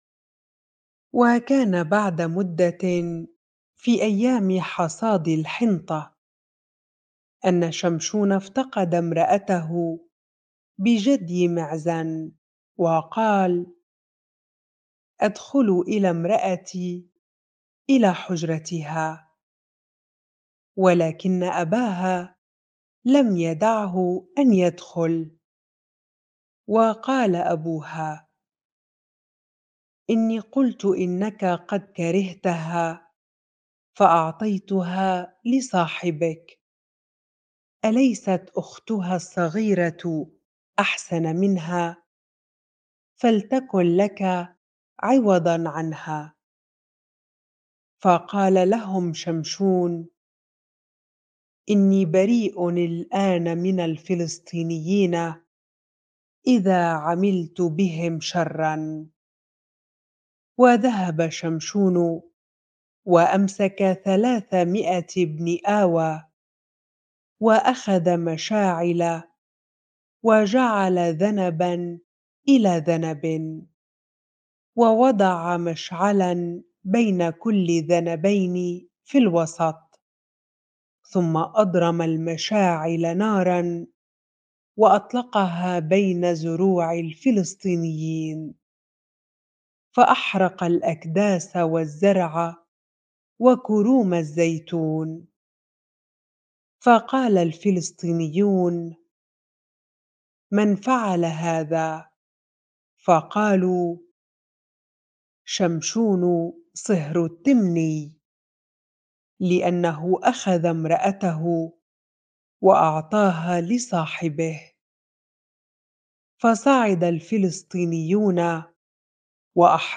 bible-reading-Judges 15 ar